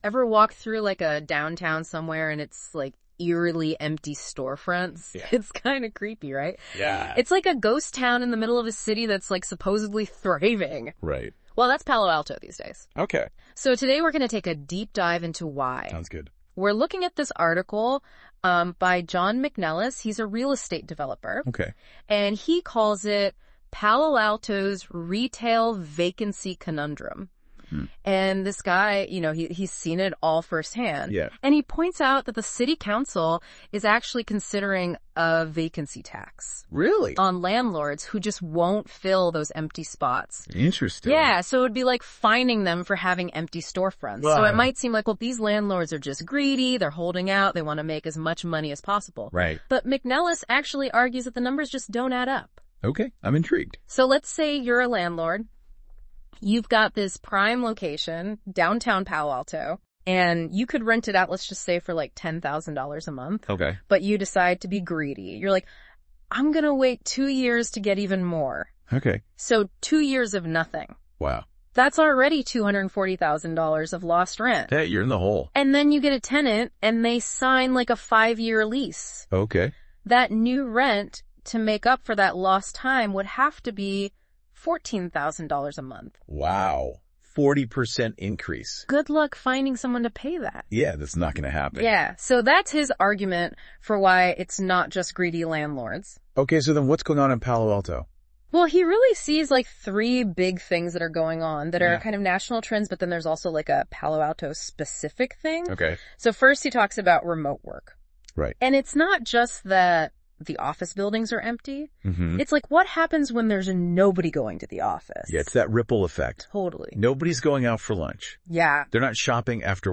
Sounding like typical morning talk show hosts, the man and woman were cheerful, clever, on point and 100 percent fake. Mellifluous, happy talk robots that I never would have guessed were bogus.
AI-Generated Article Commentary